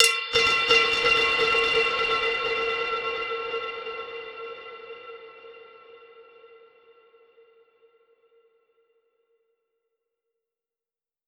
Index of /musicradar/dub-percussion-samples/85bpm
DPFX_PercHit_D_85-07.wav